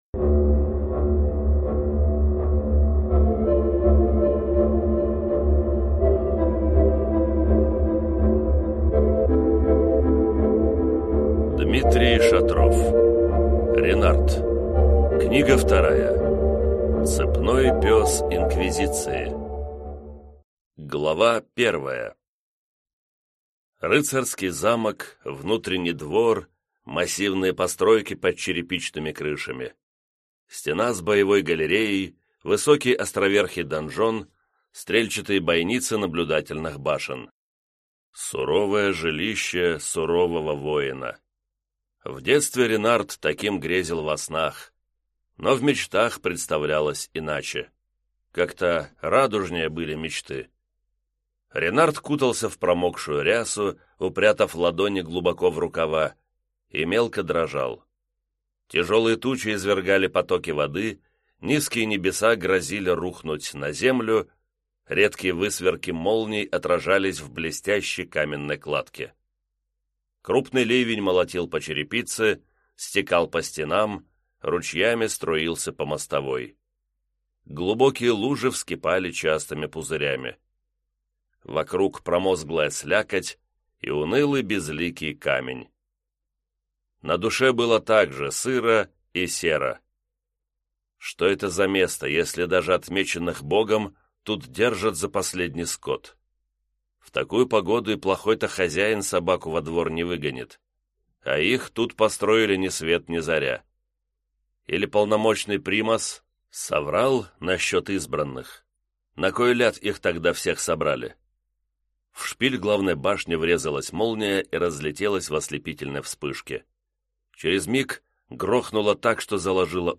Аудиокнига Ренард. Книга 2. Цепной пёс инквизиции | Библиотека аудиокниг